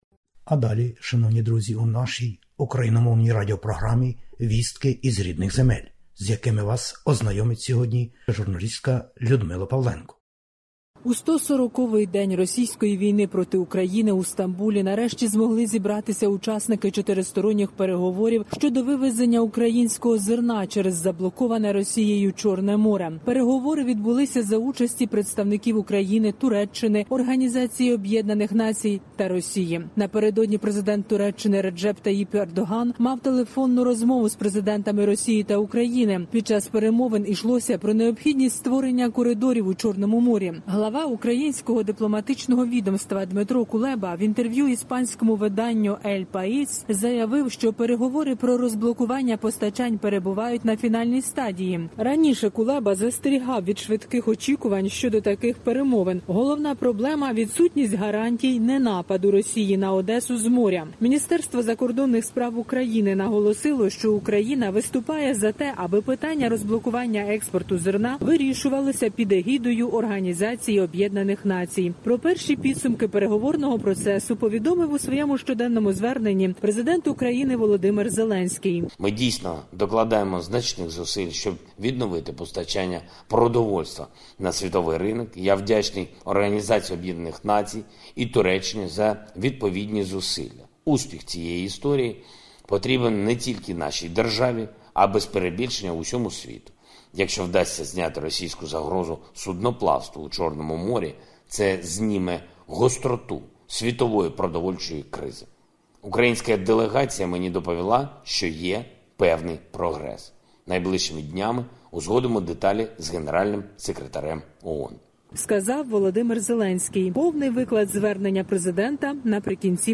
Добірка новин із героїчної України. Війна в Україні та зустріч у Стамбулі щодо транспортування українського зерна.